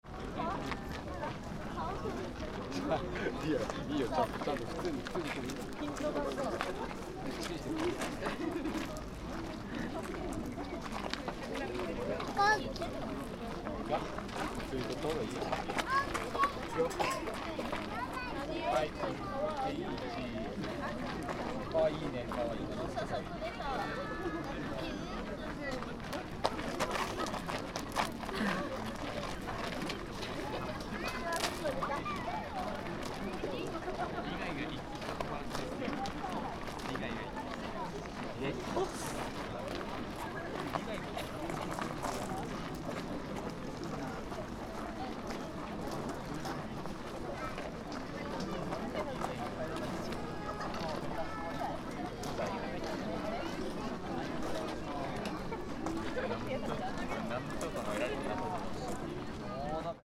Parking area in front of Gokoku Shrine
Many visitors ceaselessly came to enjoy watching the cherry blossoms at night. ♦ Despite a weekday (Thursday) night around 20:00, some families with small children remained.